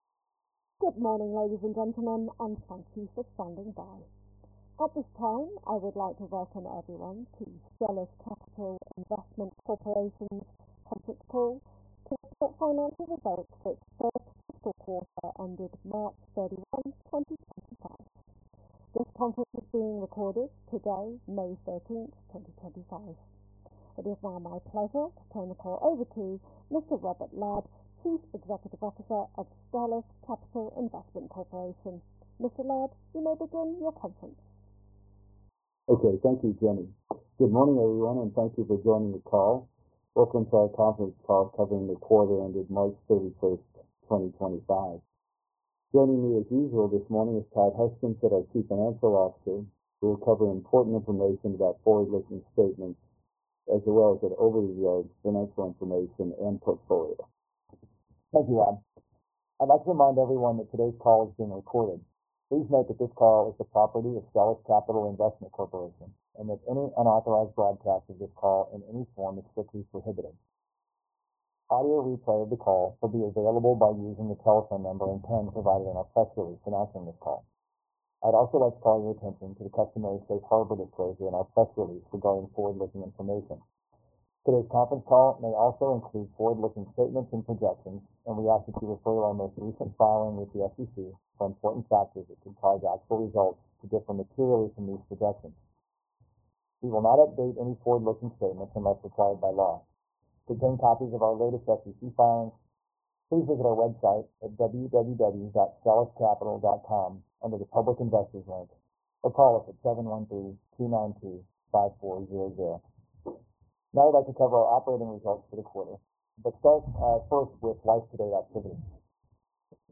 May 13, 2025 SCIC Q1 2025 Earnings Call Audio